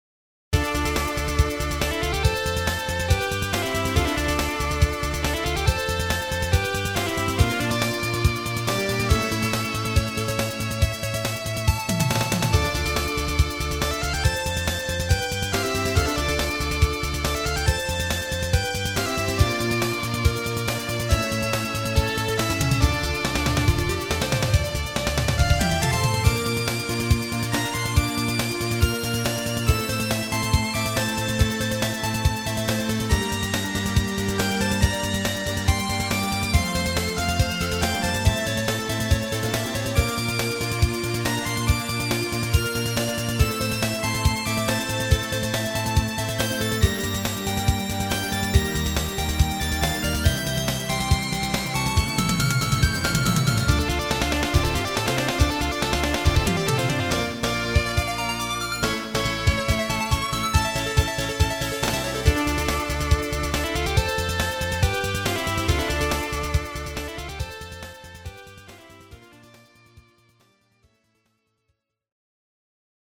FM音源とMIDIの同期を取るテスト用に作った曲です。
※音源はＳＣ８８Ｐｒｏ＋ＦＭ音源